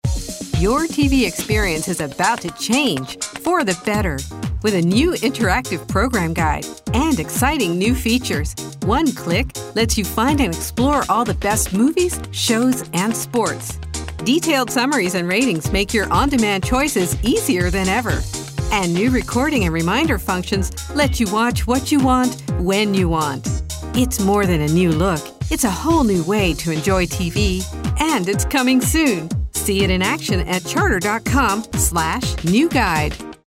a smooth and resonant voice
On Demand TV (energetic)